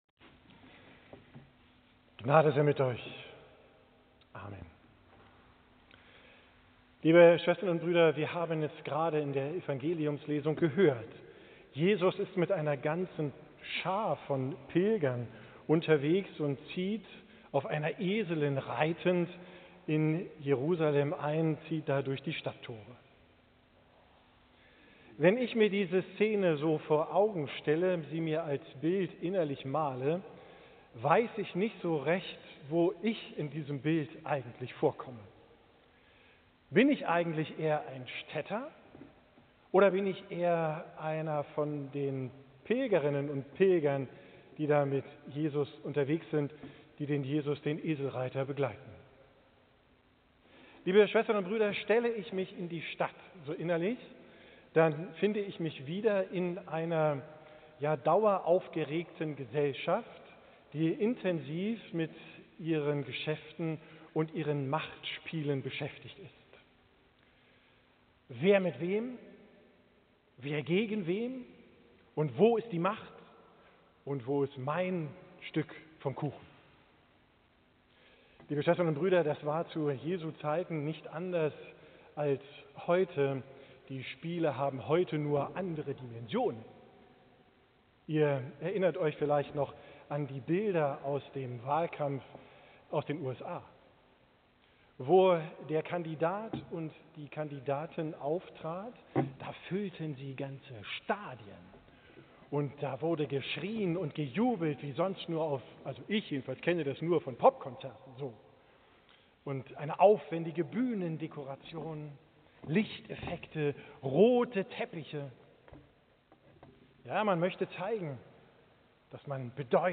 Evangelium und Predigttext Matthäus 21: 1 Als sie nun in die Nähe von Jerusalem kamen, nach Betfage an den Ölberg, sandte Jesus zwei Jünger voraus 2 und sprach zu ihnen: Geht hin in das Dorf, das vor euch liegt.